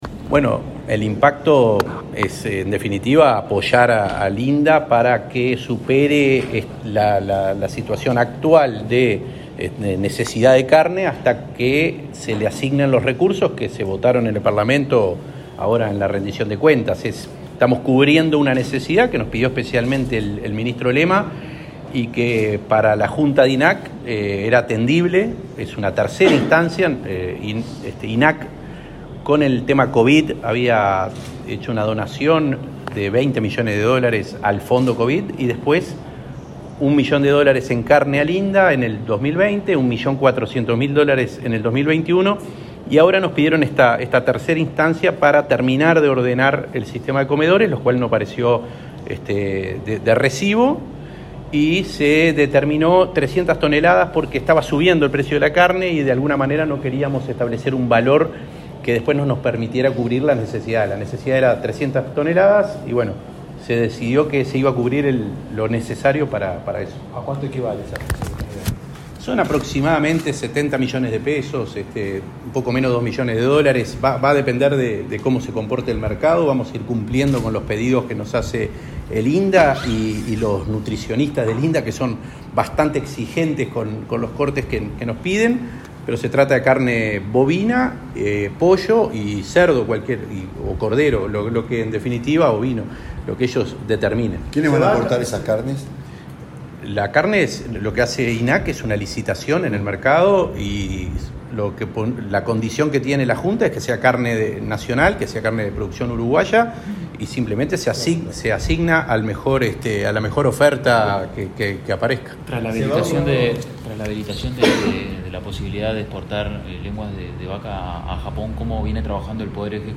Declaraciones del presidente del INAC, Conrado Ferbes
Declaraciones del presidente del INAC, Conrado Ferbes 10/11/2022 Compartir Facebook X Copiar enlace WhatsApp LinkedIn El presidente del Instituto Nacional de Carnes (INAC), Conrado Ferbes, participó en el lanzamiento del tercer plan de apoyo al sistema nacional de comedores, que se realizará con carne provista por ese organismo. Luego dialogó con la prensa.